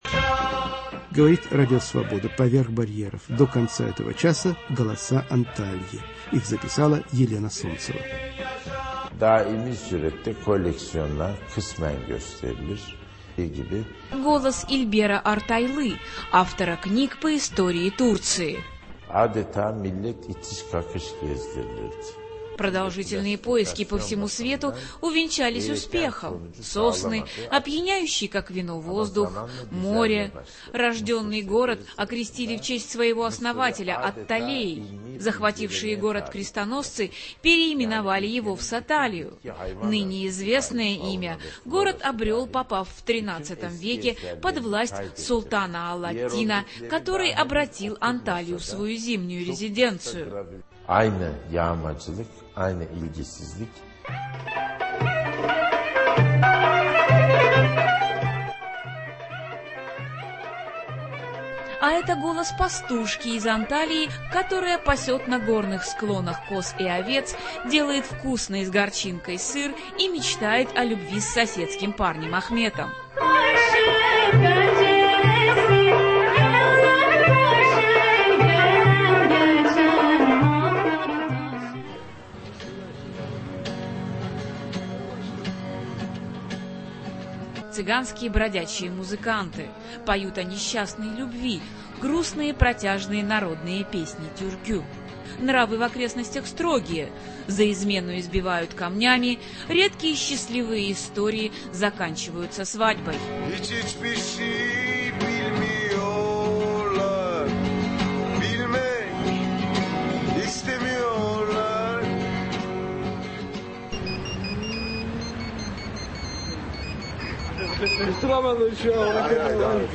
"Голоса Антальи". Акустический портрет популярного турецкого курорта